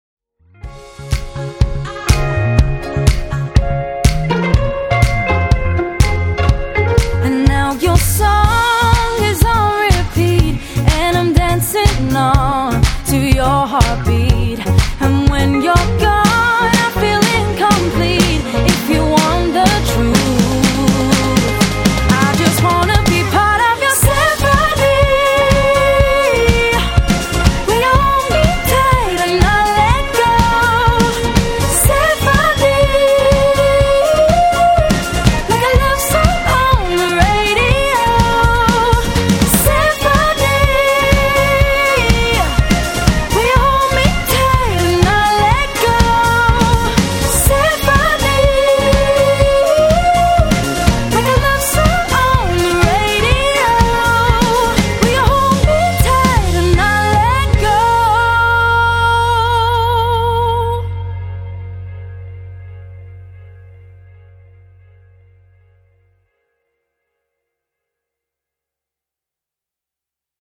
Contemporary Female Fronted Function Band